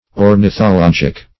Meaning of ornithologic. ornithologic synonyms, pronunciation, spelling and more from Free Dictionary.
Search Result for " ornithologic" : The Collaborative International Dictionary of English v.0.48: Ornithologic \Or`ni*tho*log"ic\, Ornithological \Or`ni*tho*log"ic*al\, a. [Cf. F. ornithologique.]